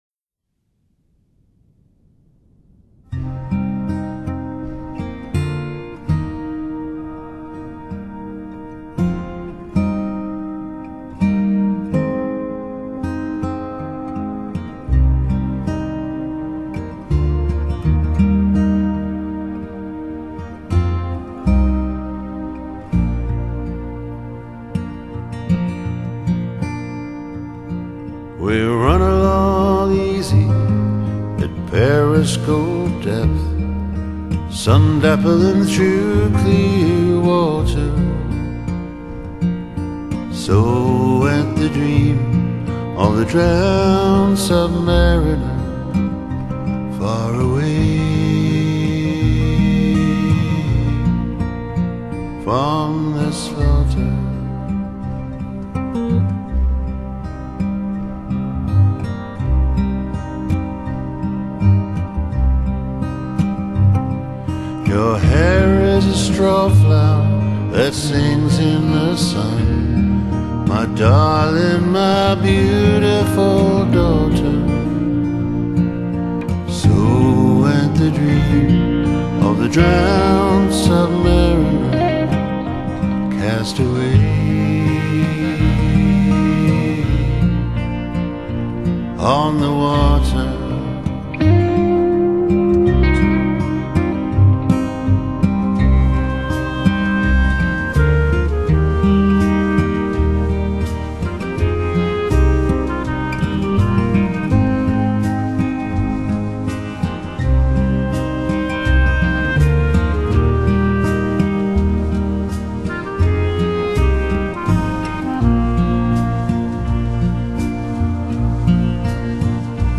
他那渾厚低沈而又富有磁性的嗓音和那甜美的Fender吉他演奏出來的Riff及Solo會令你沈醉在他的音樂世界中。